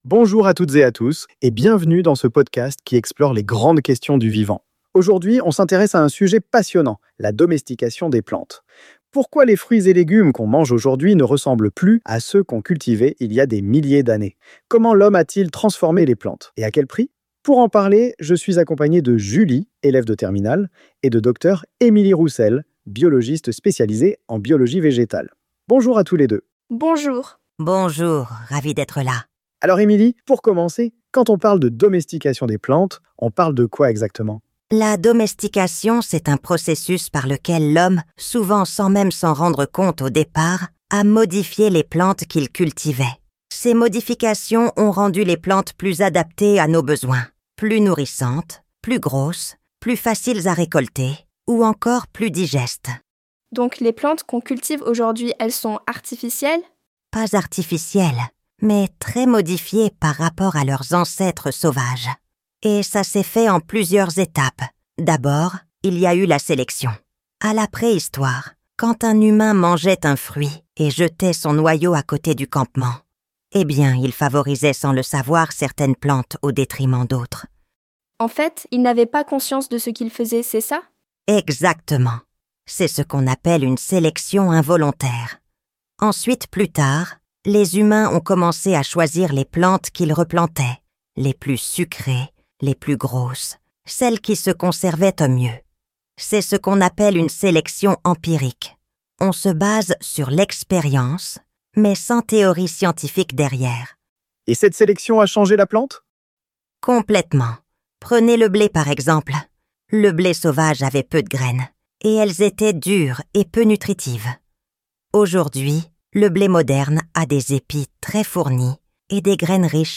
• L’experte parle avec assurance, pédagogie.
• L’animateur a un ton détendu, accessible.
Mise en voix du texte par une IA
J’ai choisi de générer chaque paragraphe séparément, en commençant par tous les passages du professeur, puis ceux de l’animateur, et enfin ceux de l’élève.
A la fin du montage, il est recommandé d’appliquer un filtre de compression pour égaliser les niveaux.